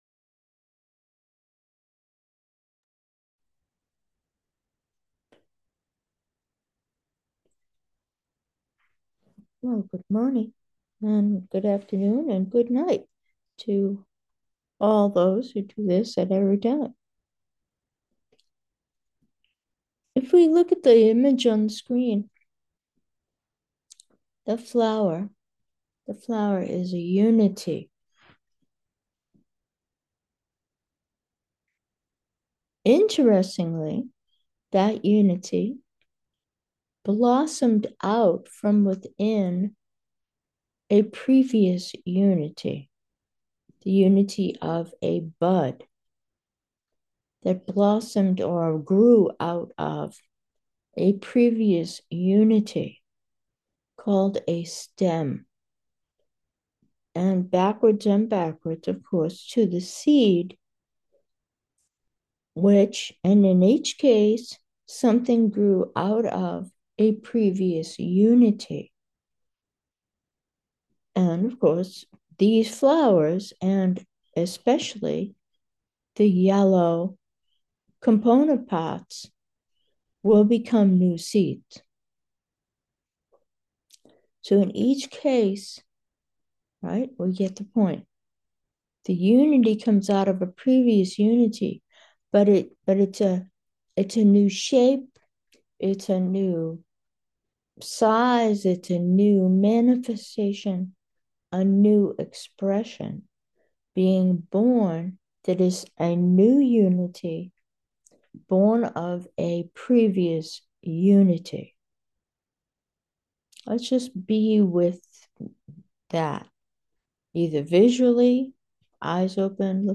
Meditation: unity born from unity | Blazing Light, Love's Song